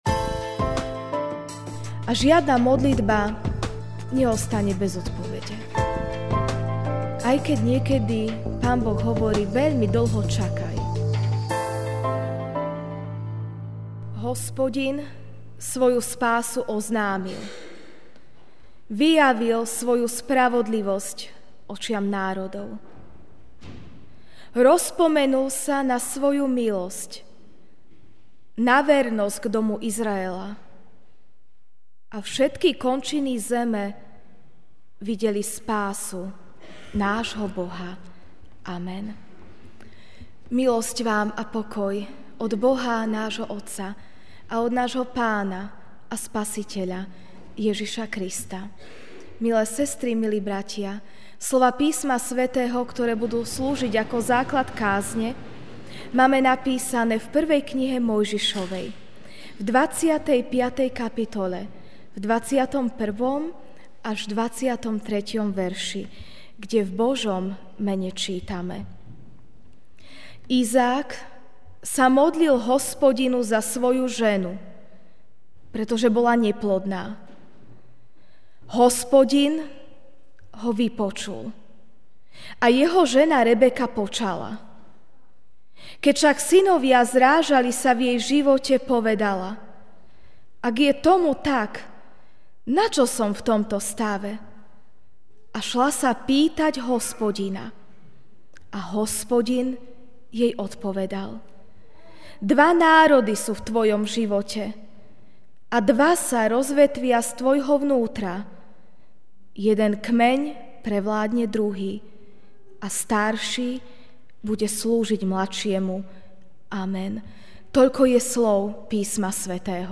máj 01, 2016 Rogate – Modlite sa MP3 SUBSCRIBE on iTunes(Podcast) Notes Sermons in this Series Ranná kázeň: (1M 25, 21-23) Izák sa modlil k Hospodinovi za svoju ženu, pretože bola neplodná.